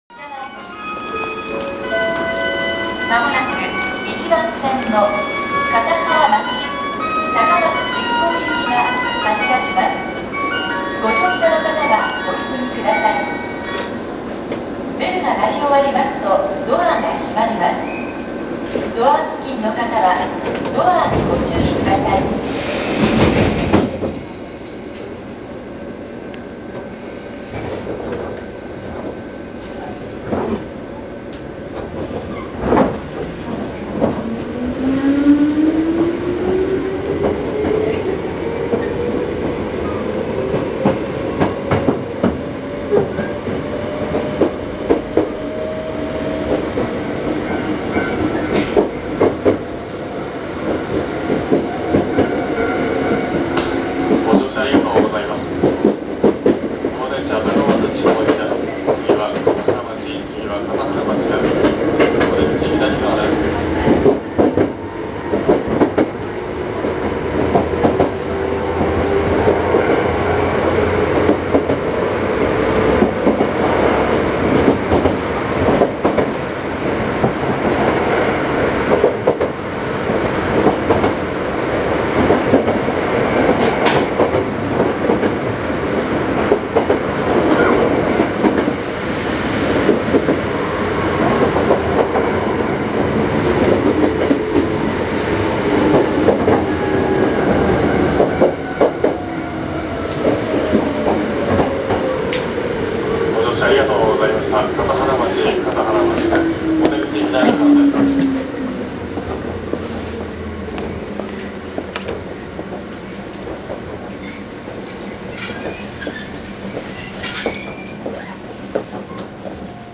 そのため、音については元京急の車両たちとなんら変わりありません。
・1100形走行音
【琴平線】瓦町→片原町（2分8秒：695KB）…1103にて。
1080形とモーターは揃えられているので、他の京急勢と音は変わりません。
1103kawaramachi-kataharamachi.WAV